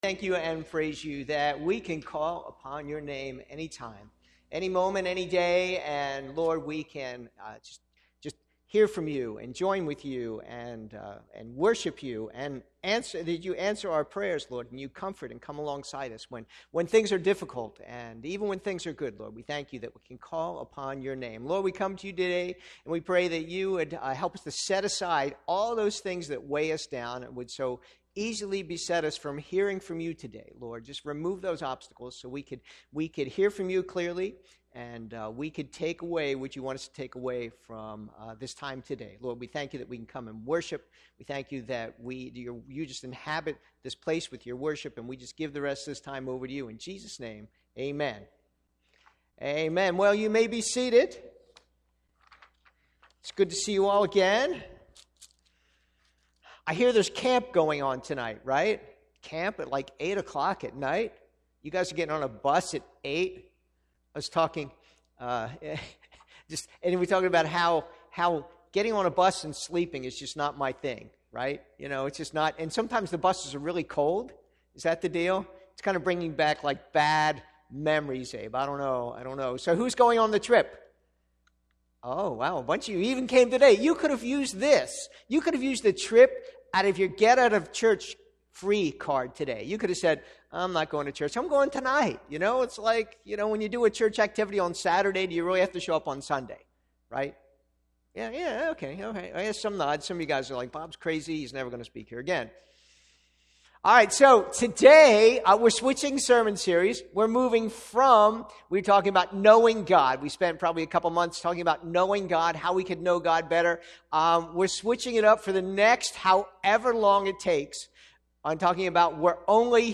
A message from the series "English Sermons."